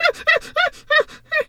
hyena_laugh_short_09.wav